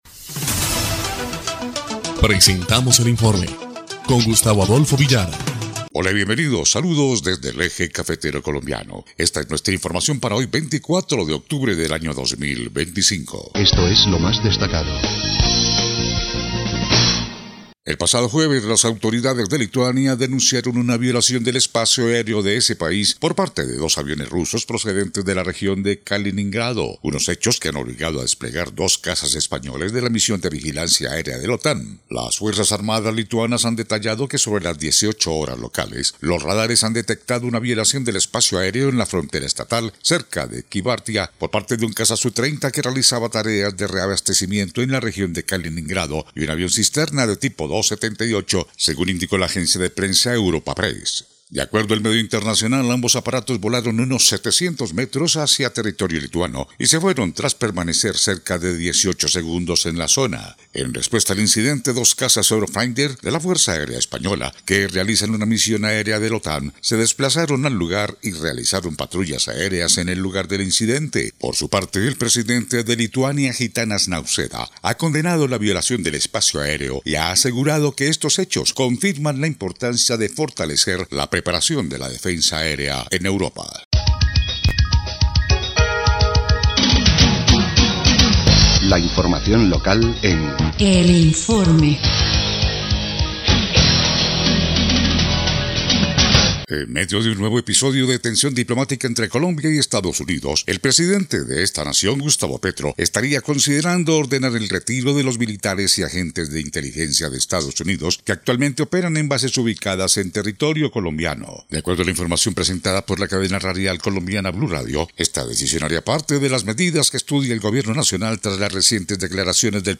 EL INFORME 3° Clip de Noticias del 24 de octubre de 2025